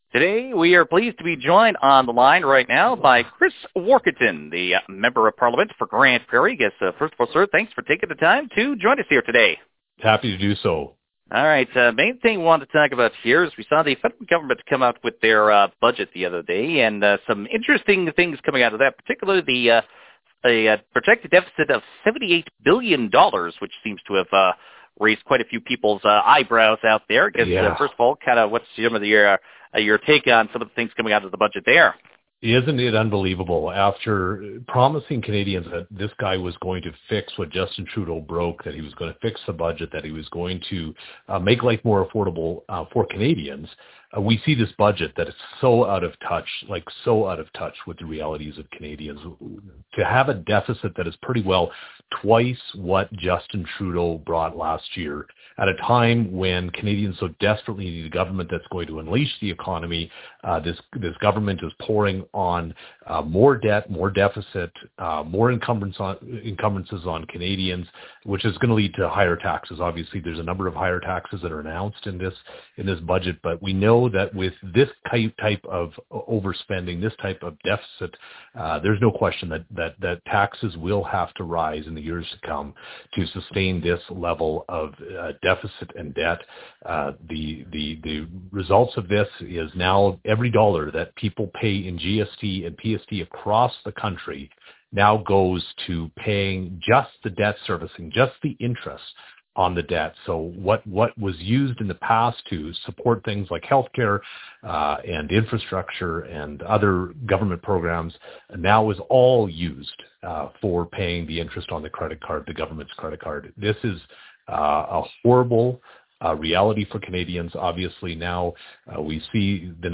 warkentin-interview-november-10.wav